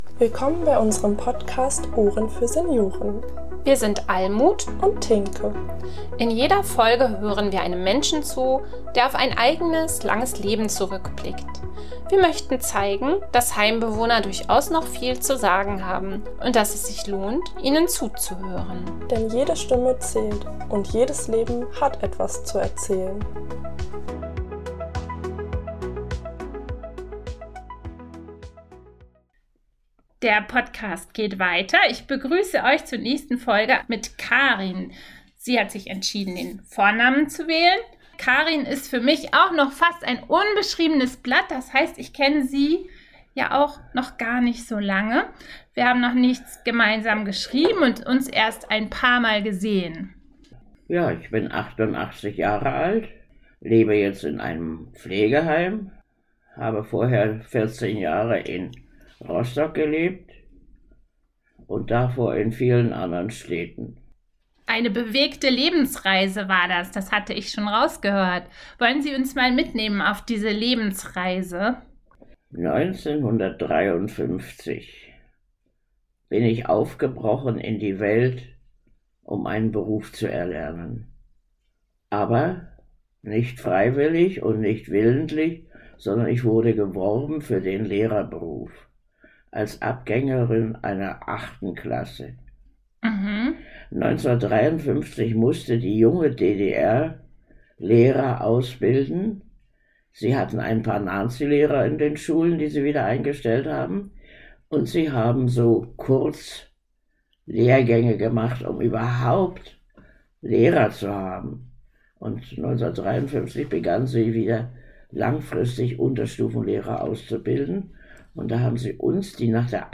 Eine resolute, alte Dame nimmt uns mit auf ihre spannende Lebensreise und zeigt ihre weiche Seite, wenn sie an ihre Arbeit mit Kindern denkt.